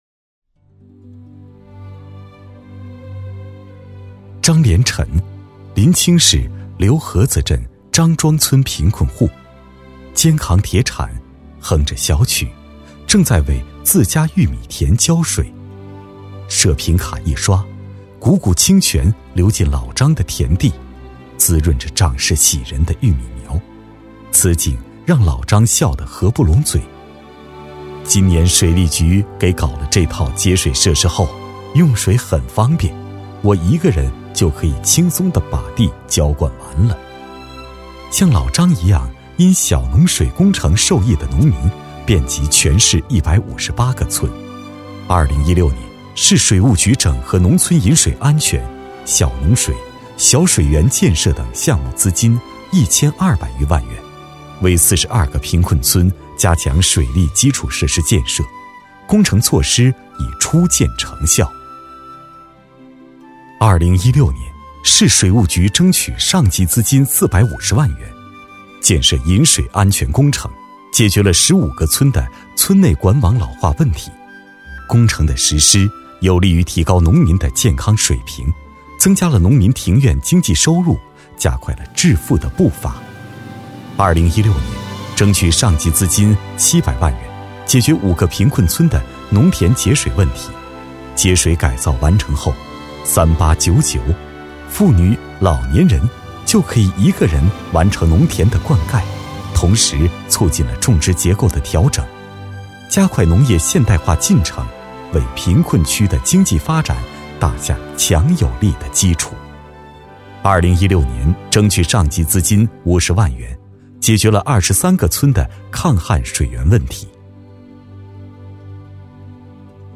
配音题材：政府宣传片配音
配音员：男国240